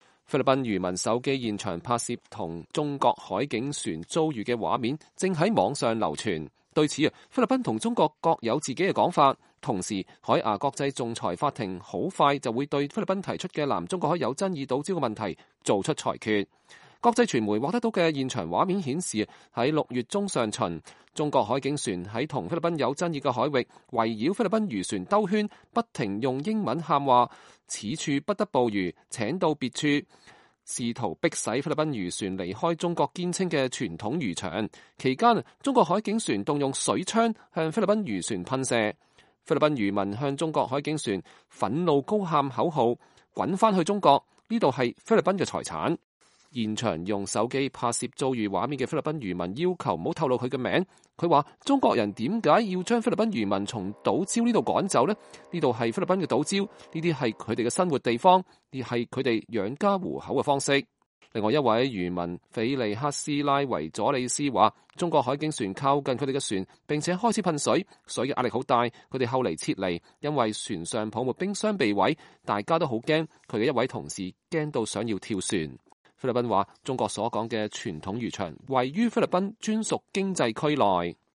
菲律賓漁民手機現場拍攝的與中國海警船遭遇的畫面正在網上流傳。
國際傳媒獲得的現場畫面顯示，6月中上旬，中國海警船在與菲律賓有爭議的海域，圍繞菲律賓漁船兜圈，不停用英語喊話，“此處不得捕魚！”“請到別處”，試圖迫使菲律賓漁船離開中國堅稱的“傳統漁場”。期間中國海警船動用水槍向菲律賓漁船噴射。
菲律賓漁民向中國海警船憤怒高喊口號：“滾回中國去！”，“這是菲律賓的財產”！